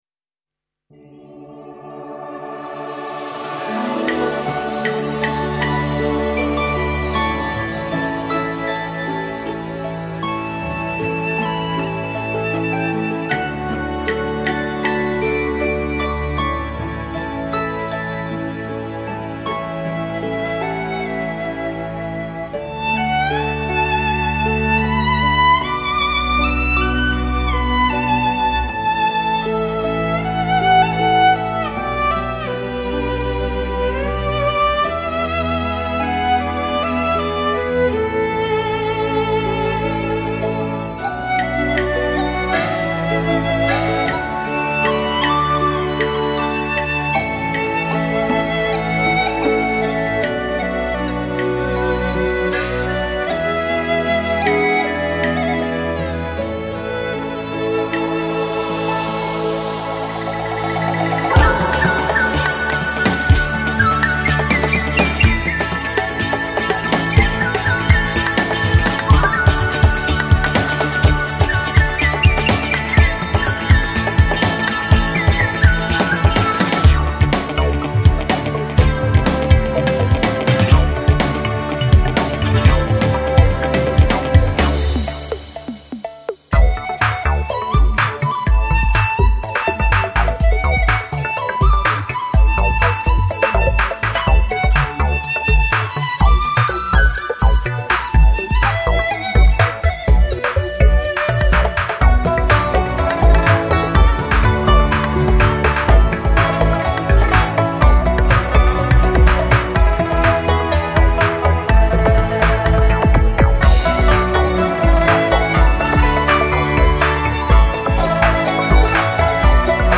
音乐类型:轻音乐
史上第一次，"管弦乐队+流行乐队+特色乐器"，全面创新演绎中华经典音乐
唯美、新鲜、浪漫、欢乐。
绝对真实的乐器演奏，让您摆脱midi、电子音乐的困扰，享受一次完美的听觉饕餮。